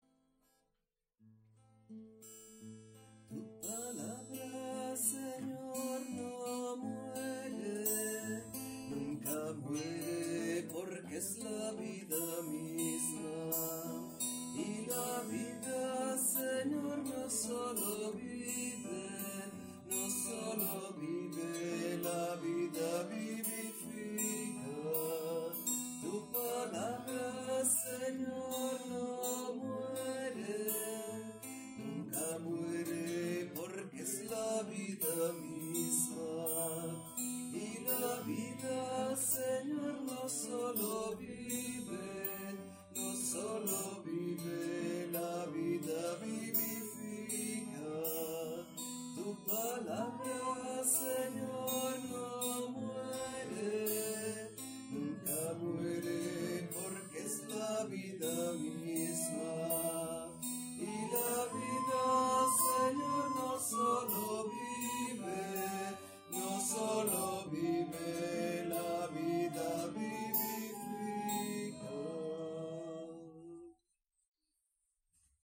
Pregària de Taizé a Mataró... des de febrer de 2001
Parròquia M.D. de Montserrat - Diumenge 26 de febrer de 2023
Vàrem cantar...